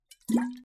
瓶装饮料
描述：人喝完水后液体打在金属水瓶底部的声音。
Tag: cherplunk 回声 饮料 液体